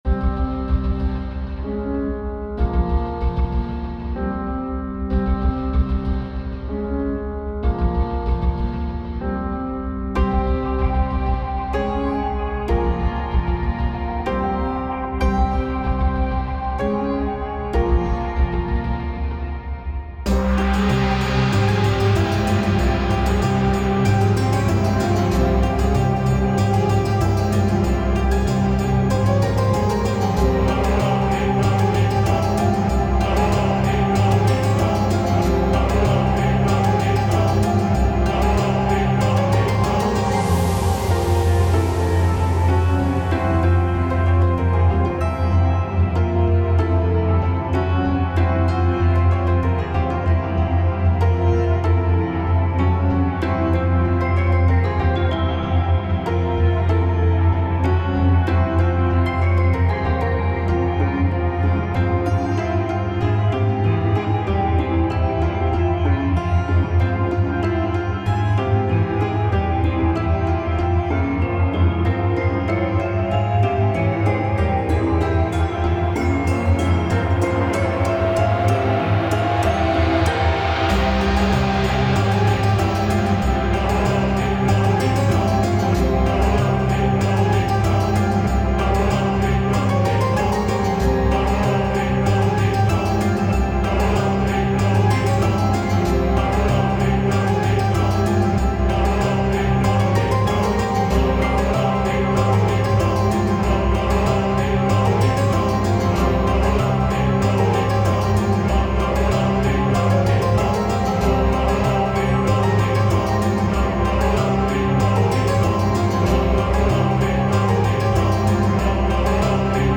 ダブルハーモニックメジャーを主軸にしたフレージング、銅鑼とコーラスにより強大な存在の雰囲気を作っている。